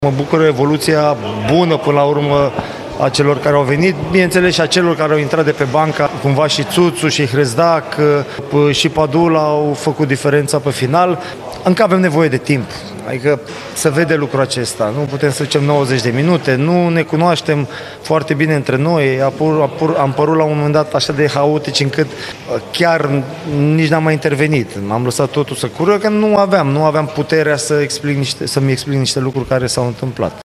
Antrenorul UTA-ei, Adrian Mihalcea, mulțumit de aportul celor veniți de pe bancă: